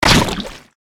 AA_throw_wedding_cake_cog.ogg